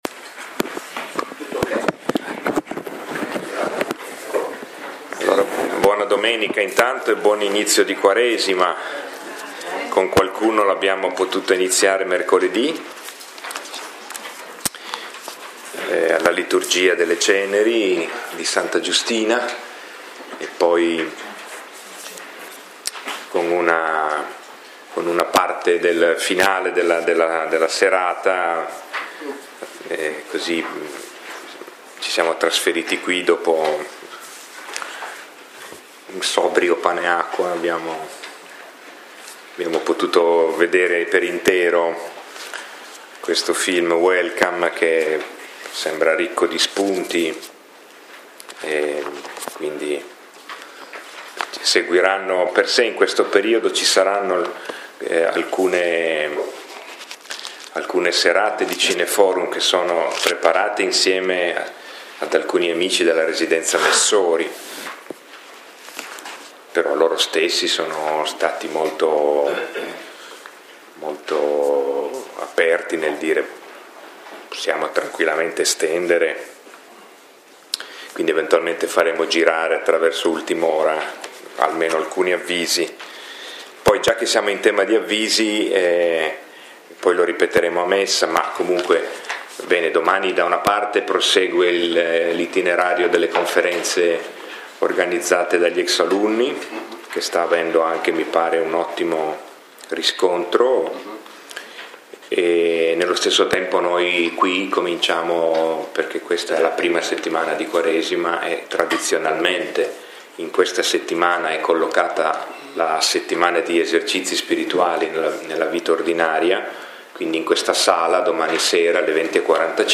Lectio 5 – 18 febbraio 2018